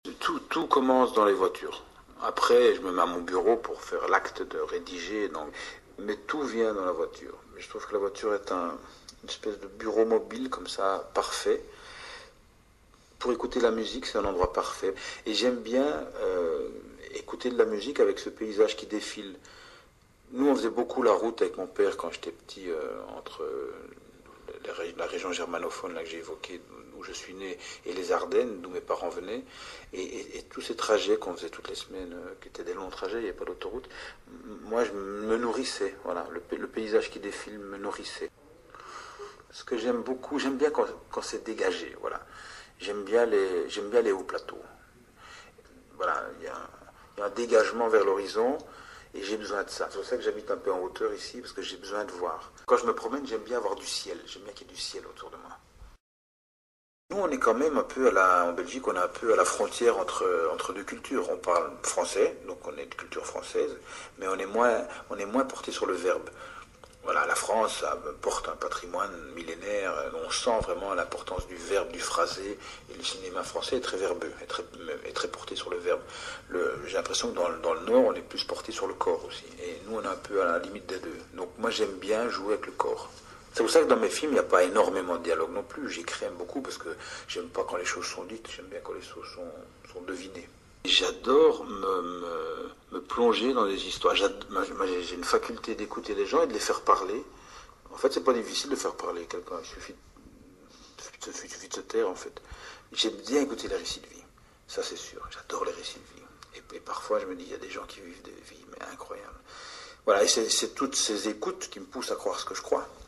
Il est belge. Cinéaste.
Avec son accent belge.
En quelques mots, on sait qu’il est belge, ou en tout cas pas du sud de la France !
En Belgique (et en France ailleurs que dans le sud), on « mange » les syllabes.